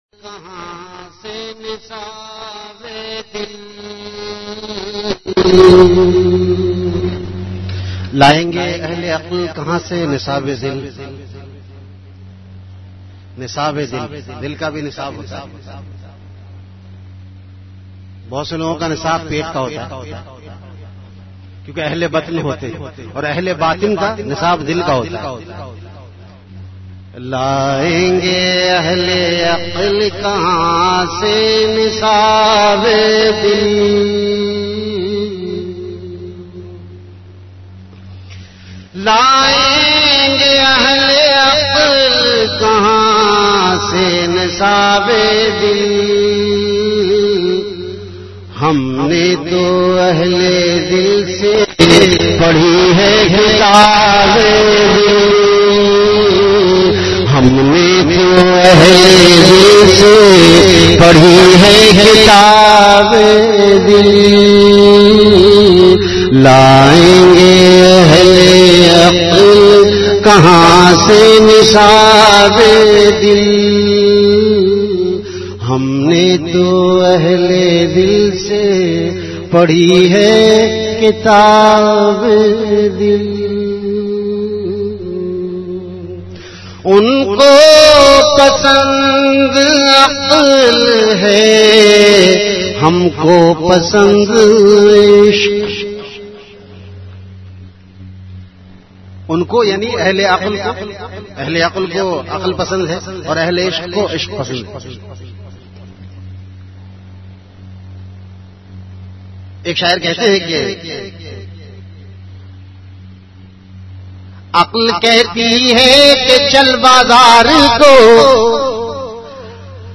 Delivered at Home.
Venue Home Event / Time After Isha Prayer